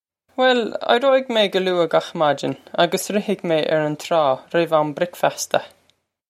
Pronunciation for how to say
Well, eye-ro-ig may guh loo-ah gokh modgin a-gus rih-hig may air on traw rih-v om brik-fass-ta.
This is an approximate phonetic pronunciation of the phrase.